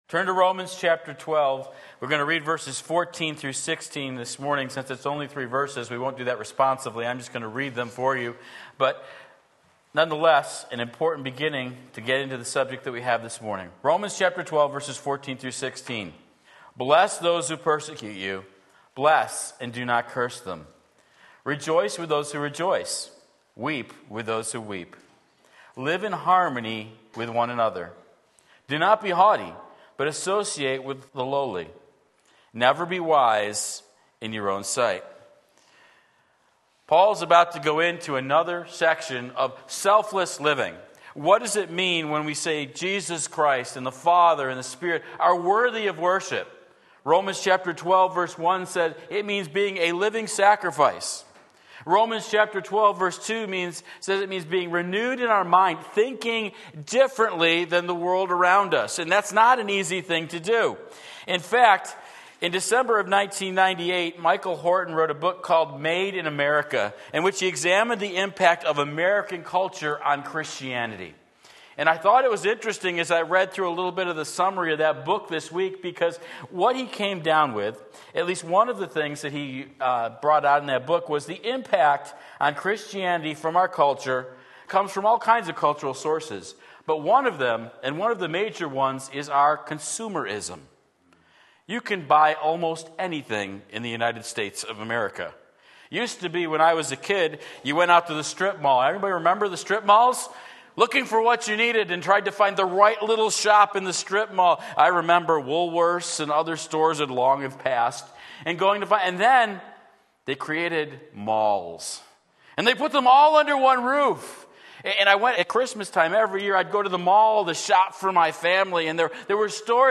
Part 2 Romans 12:14-16 Sunday Morning Service